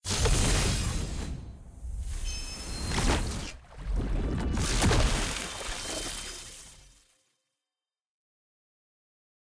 sfx-exalted-rolling-ceremony-single-gold-anim.ogg